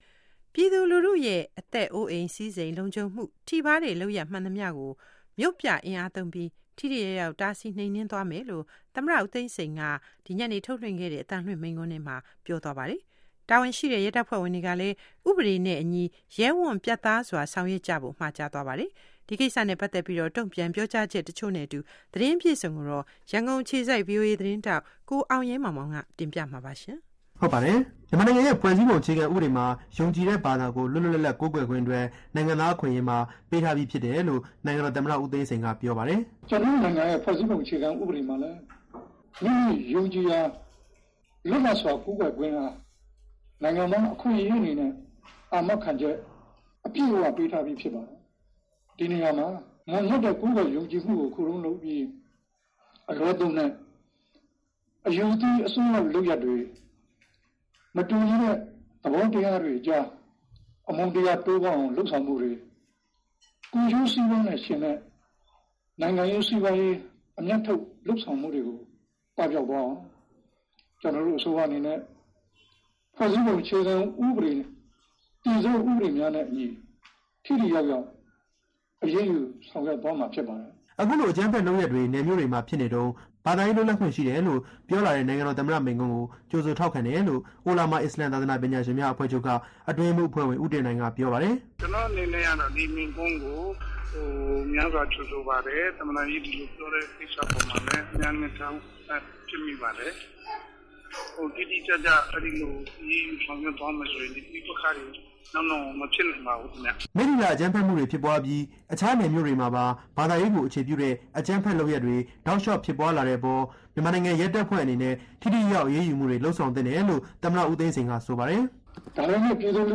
သမ္မတဦးသိန်းစိန် မိန့်ခွန်း